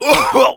Heavy_painsharp03_fr.wav